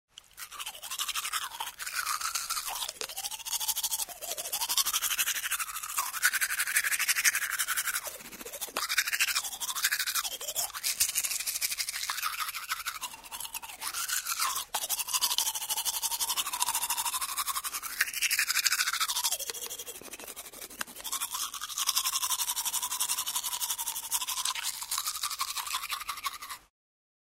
Звуки зубов
Быстрая чистка зубов